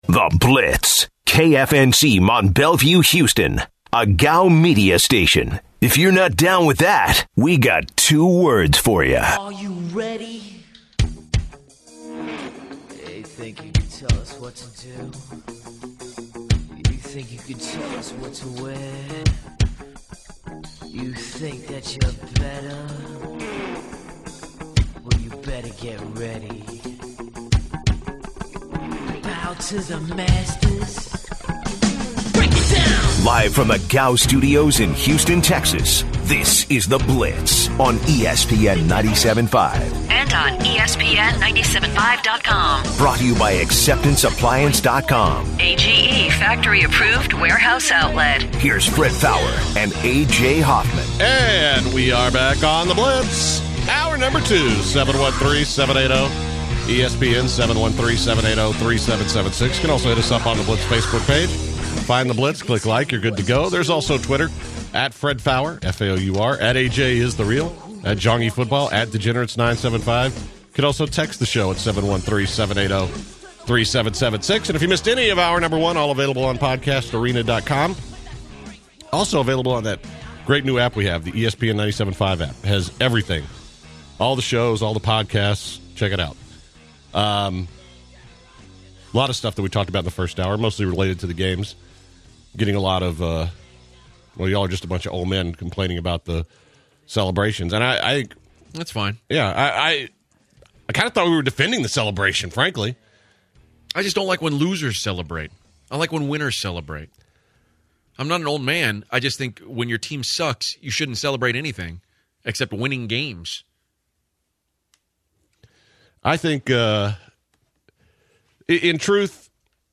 The guys also take some calls about player celebrations and the Denver-New England game. The guys also go over the depth of the 2011 NFL Draft, Cam Newton big contract, and Vince Young recent run in with the law. Stanford Routt joins the show to discuss this weekend conference championship games and Super Bowl 50.